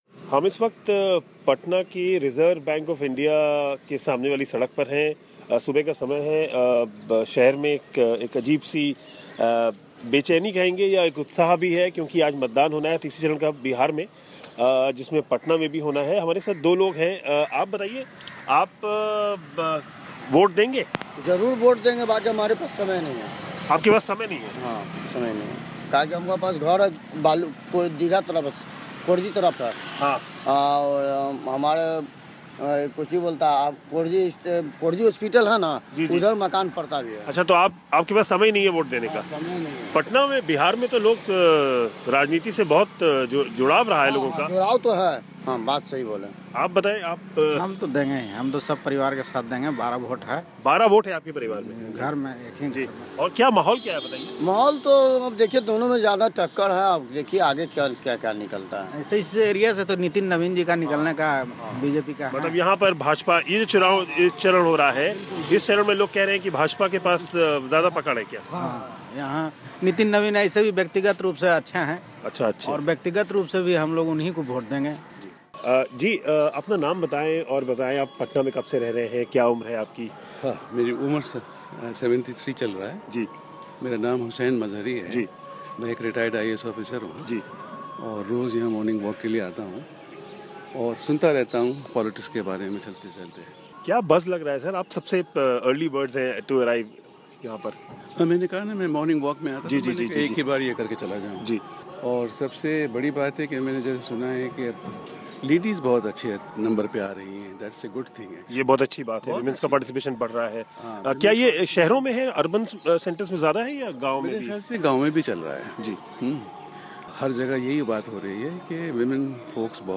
ख़ास रिपोर्ट.